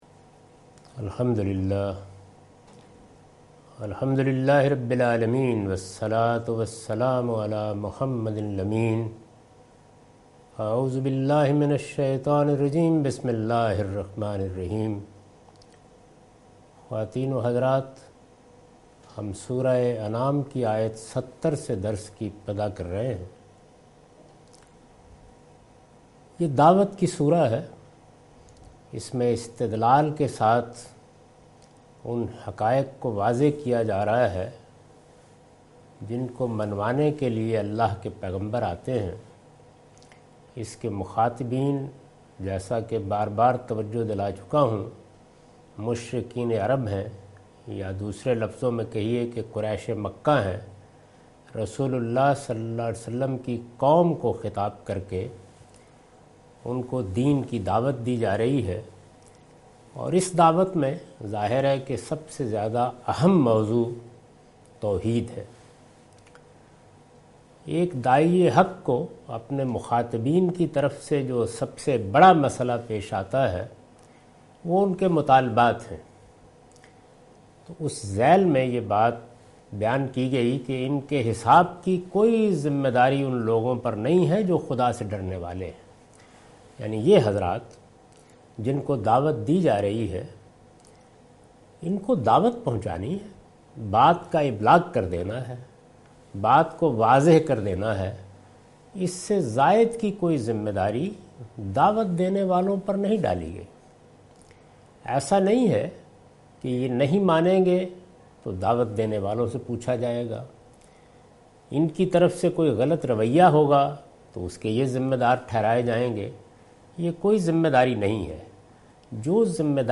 Surah Al-Anam - A lecture of Tafseer-ul-Quran – Al-Bayan by Javed Ahmad Ghamidi. Commentary and explanation of verse 70-72.